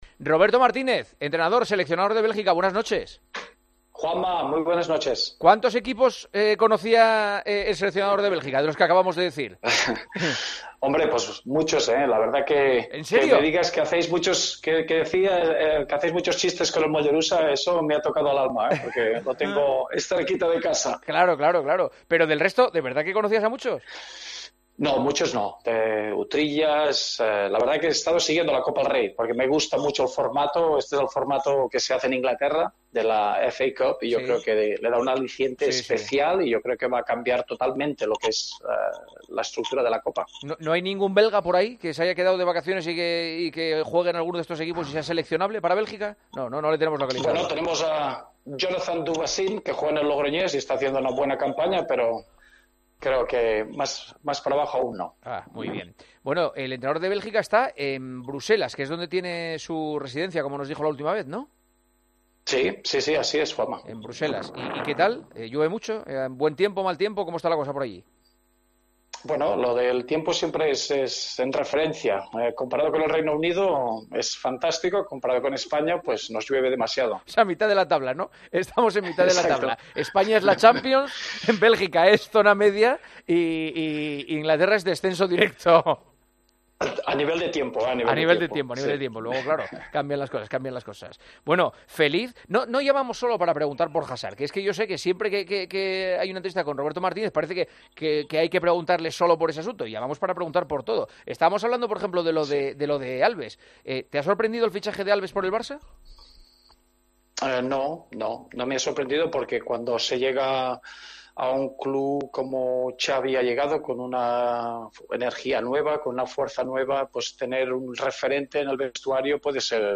Roberto Martínez ha estado este miércoles en El Partidazo de COPE tras haber clasificado a la selección belga para el Mundial de Catar 2022 .